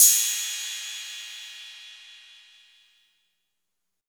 Crashes & Cymbals
MB Crash (6).wav